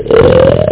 Amiga 8-bit Sampled Voice
snore.mp3